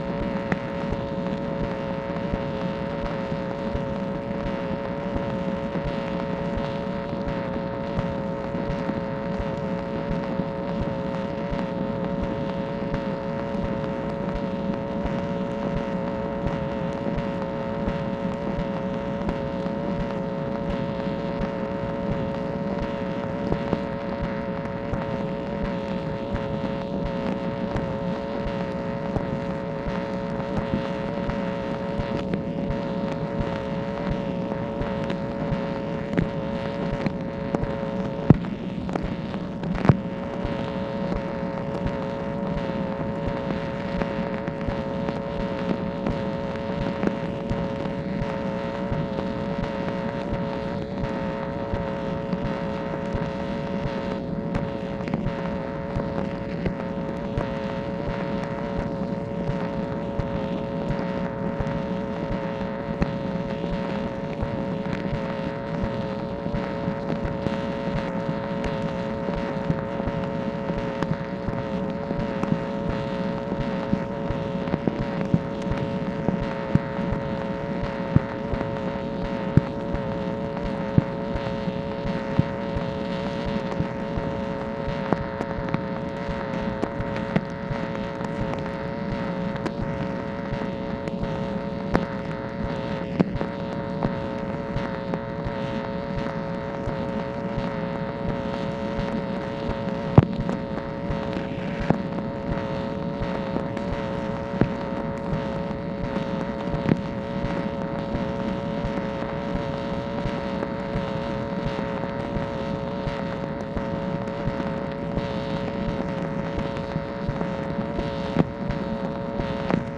MACHINE NOISE, August 27, 1968